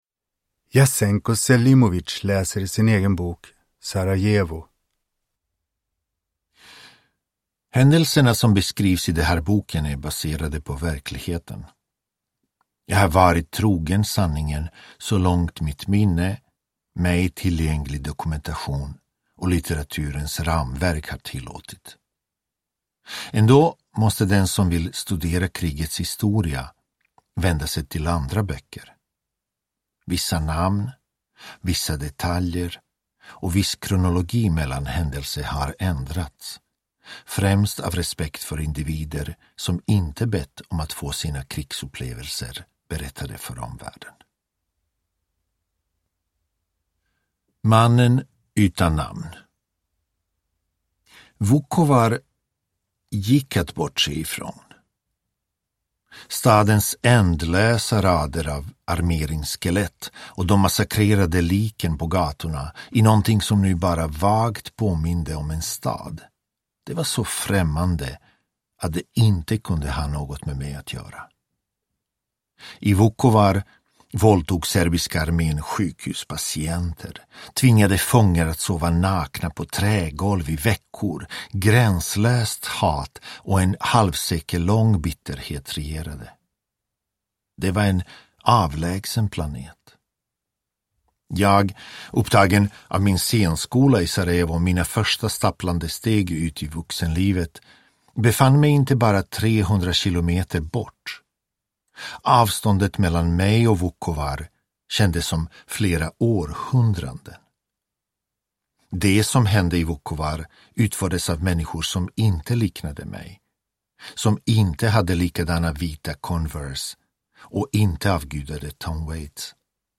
Sarajevo : minnen från en belägring – Ljudbok – Laddas ner